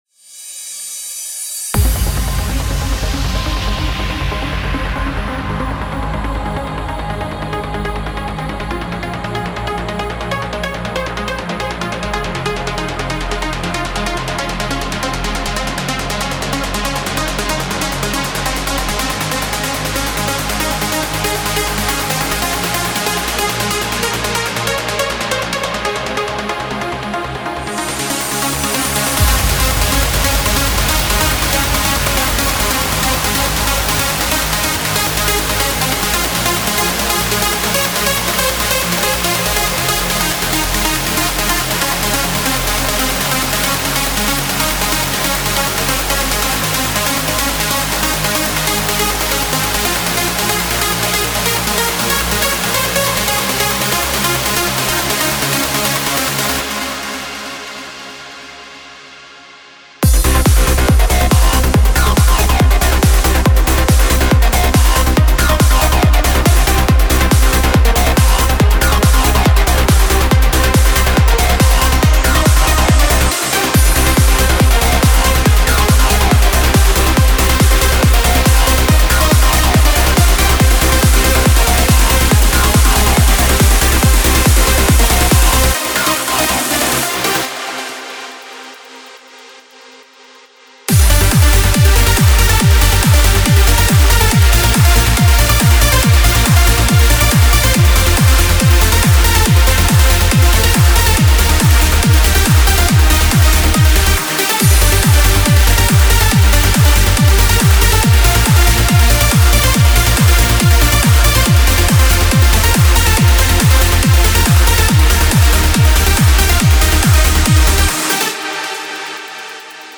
Genre: Trance Uplifting Trance